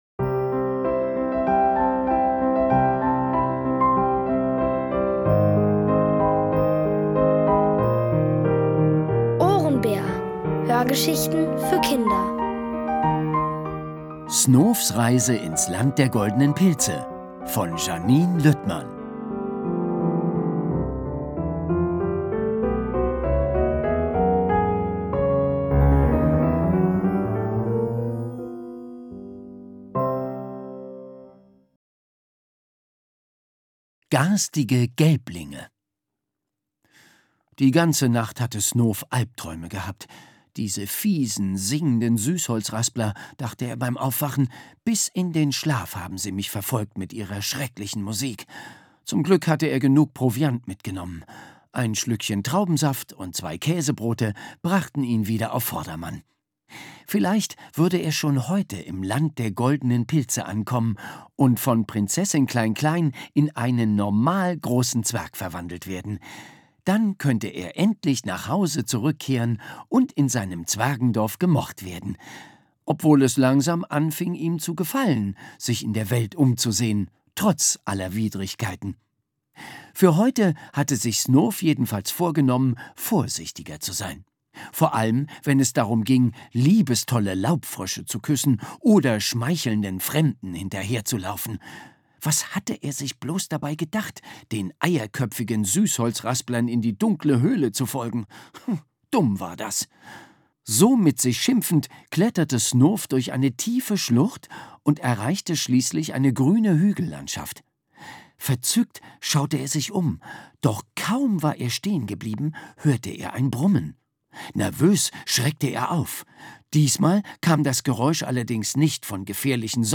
Von Autoren extra für die Reihe geschrieben und von bekannten Schauspielern gelesen.
OHRENBÄR-Hörgeschichte: Snoofs Reise ins Land der goldenen Pilze